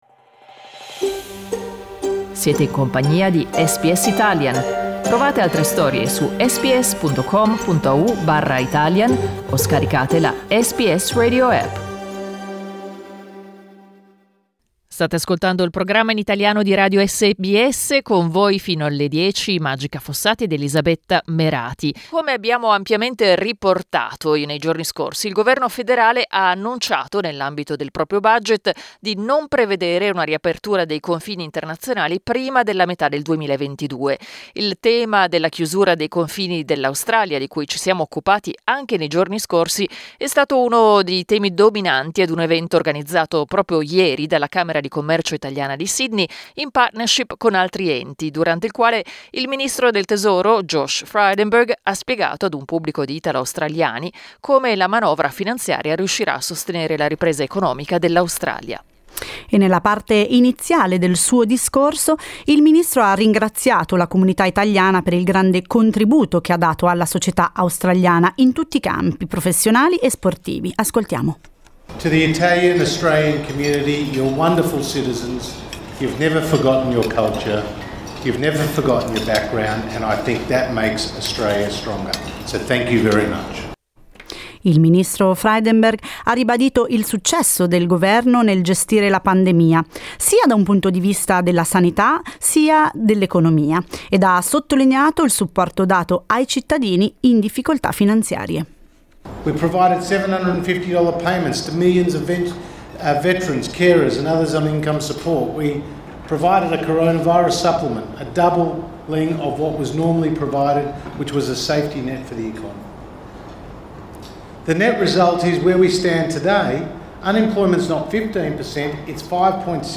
Il ministro delle Finanze Josh Frydenberg durante il suo discorso sulla Finanziaria ad un evento organizzato dalla Camera di Commercio Italiana con altri enti.